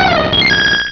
Cri de Mentali dans Pokémon Rubis et Saphir.